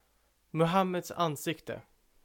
The cartoon was captioned "Muhammad's Face" (Swedish: Muhammeds ansikte [mɵˈhǎmːɛds ˈânːsɪktɛ]
Sv-Muhammeds_ansikte.ogg.mp3